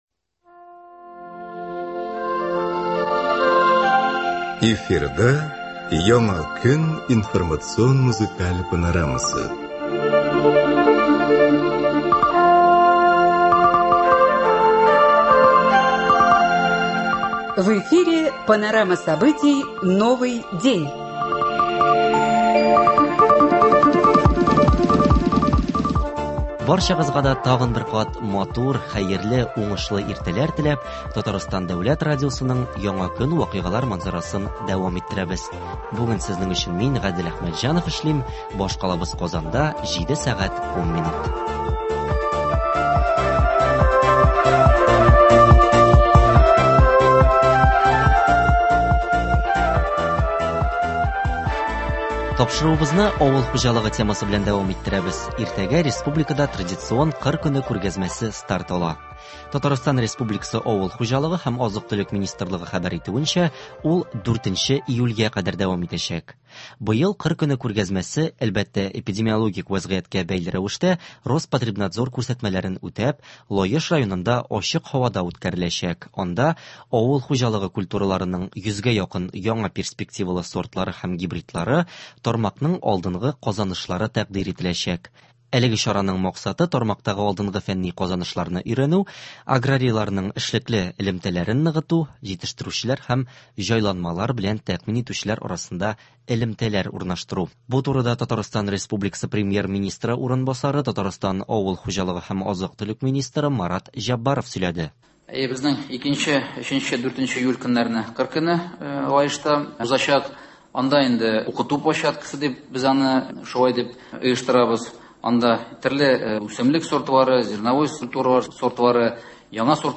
Соңгы ике елда республикада бал кортлары күпләп үлә. Бу басу-кырларда кулланыла торган пестицидлар белән бәйле.Умартачылар һәм белгечләр белән телефон аша элемтәгә чыктык.